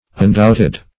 undoubted - definition of undoubted - synonyms, pronunciation, spelling from Free Dictionary
Undoubted \Un*doubt"ed\, a.